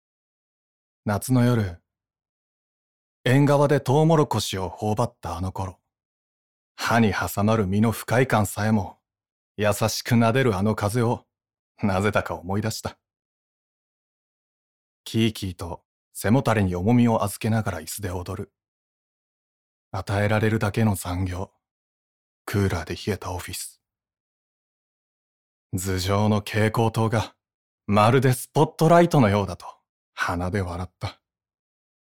出⾝地・⽅⾔ 和歌山県・関西弁
ボイスサンプル